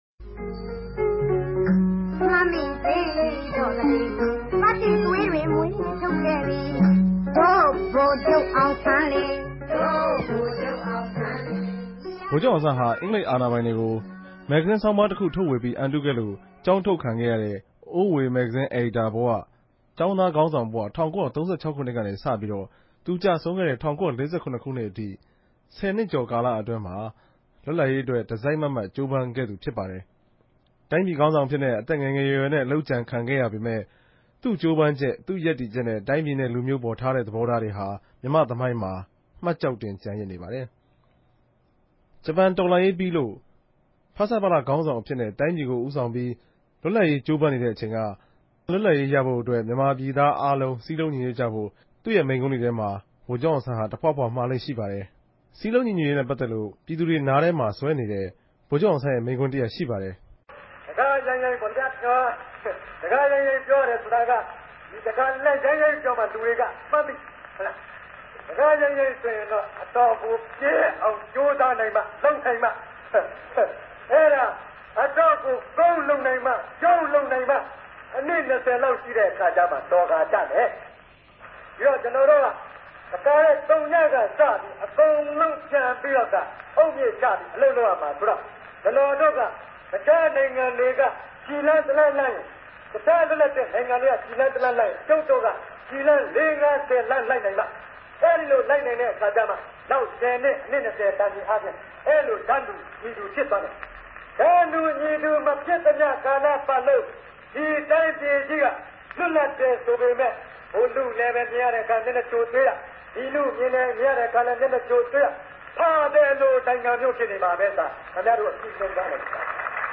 ဗိုလ်ခဵြပ်အောင်ဆန်း မိန်ႛခြန်းမဵားကောကိံြတ်ခဵက်အစီစဉ်